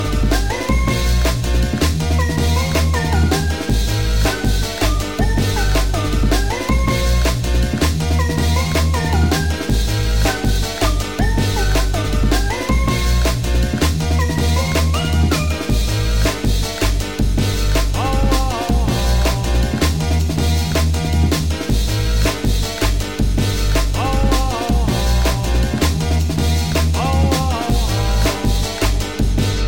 TOP > Jungle